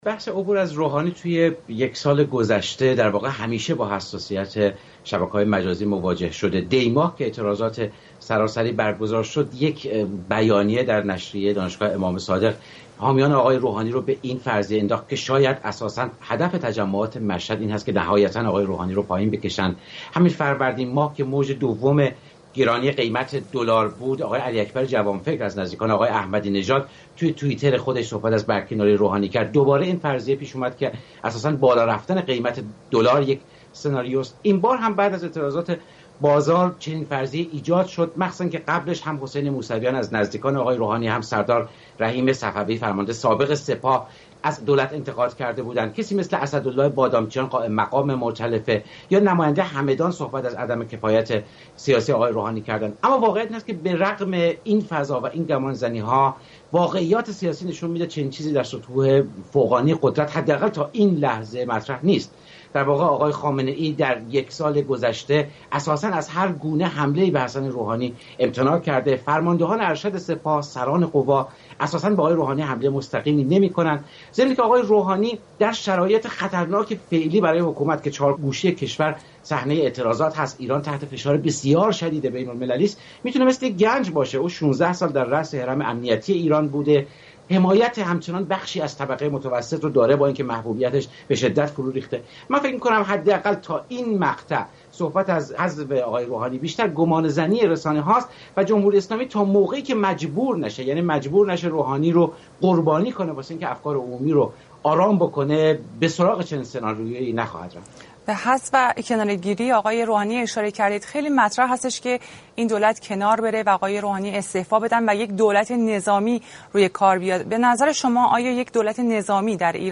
«عبور از روحانی» در شبکه‌های مجازی در گفت‌وگو